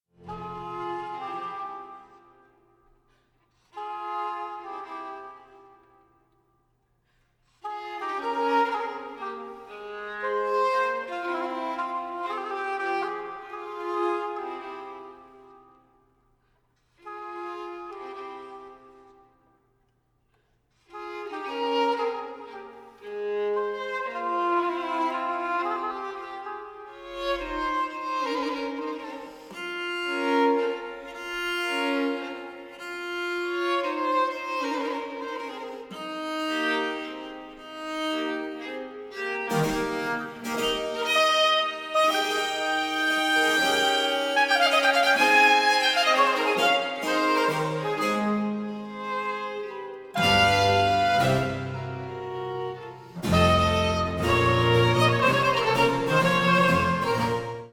baroque oboe
baroque violin
viola da gamba
harpsichord